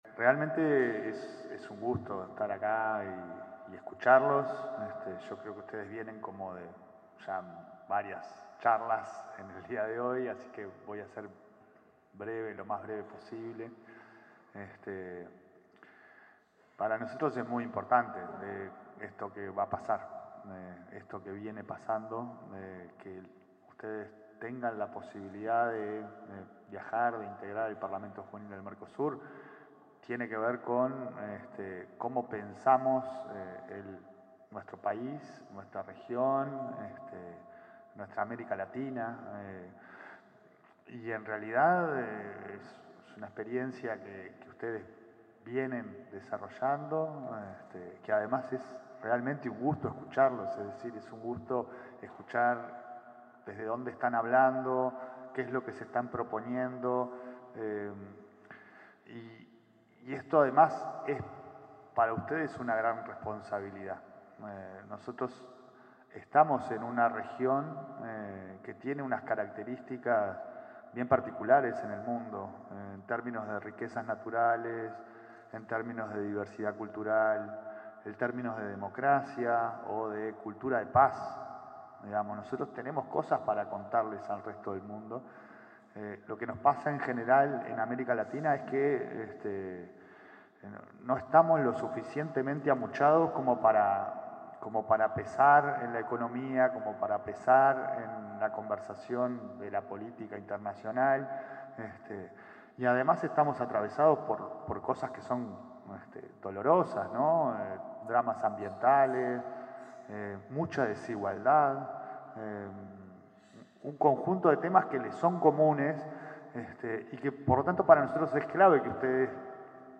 Palabras del presidente de la ANEP, Pablo Caggiani
La Administración Nacional de Educación Pública (ANEP) realizó una ceremonia de reconocimiento a jóvenes uruguayos que participarán en el Parlamento
En la oportunidad, se expresó el presidente de la ANEP, Pablo Caggiani.